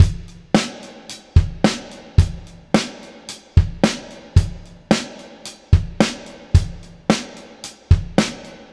• 110 Bpm Drum Loop Sample F# Key.wav
Free breakbeat - kick tuned to the F# note. Loudest frequency: 870Hz
110-bpm-drum-loop-sample-f-sharp-key-ivj.wav